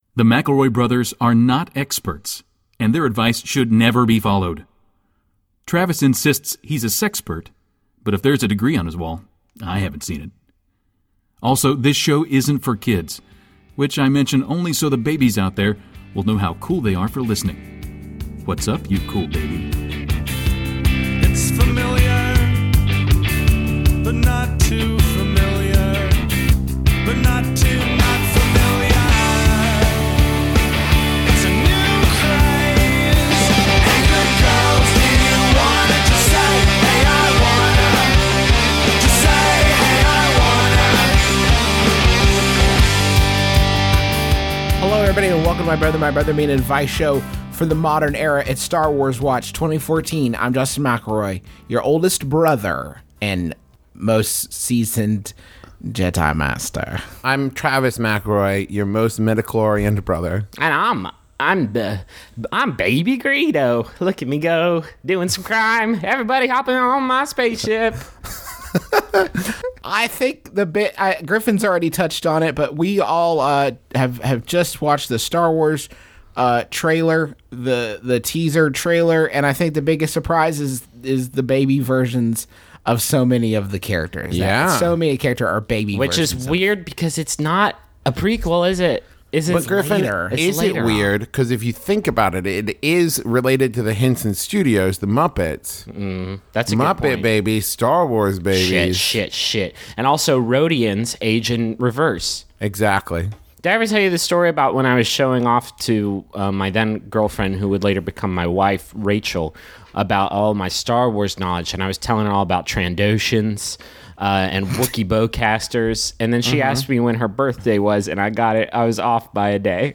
Comedy Advice